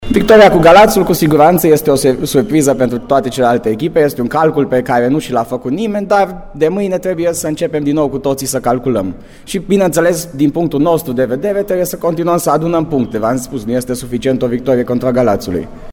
Reacțiile de după meci au fost ”culese” de la fața locului